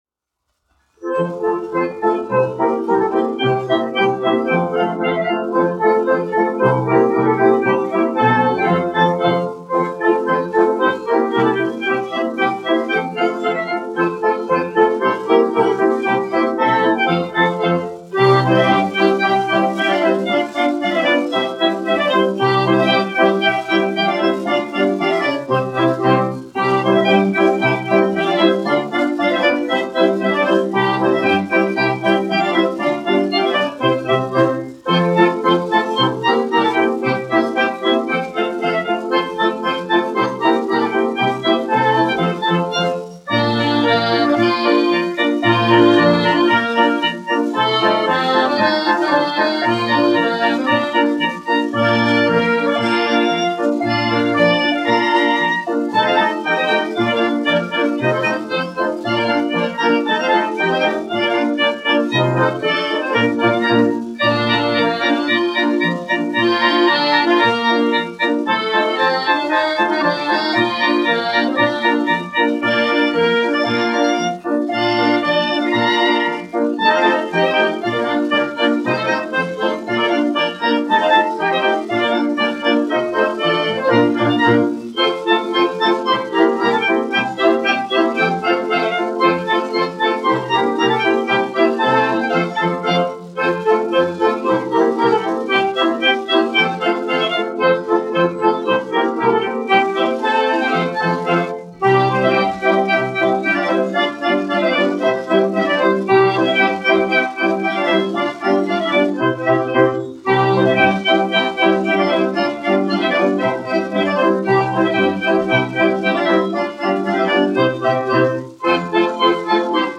1 skpl. : analogs, 78 apgr/min, mono ; 25 cm
Polkas
Tautas deju mūzika
Skaņuplate